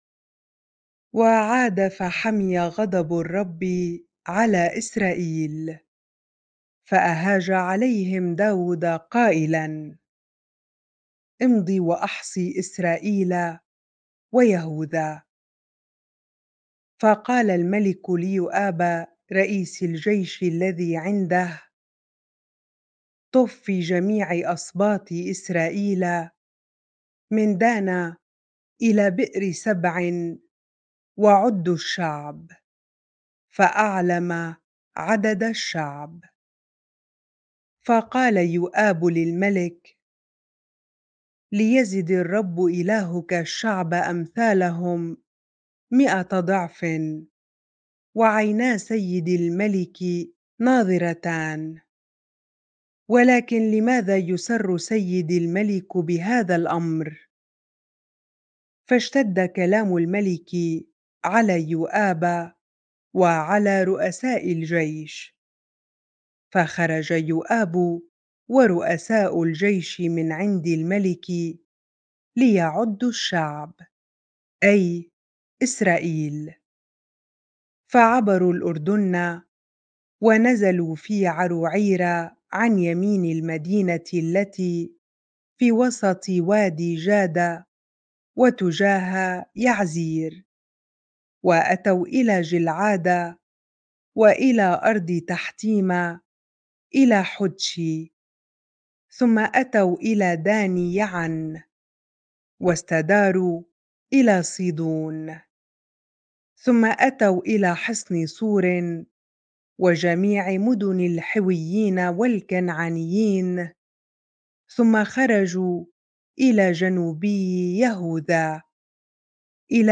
bible-reading-2Samuel 24 ar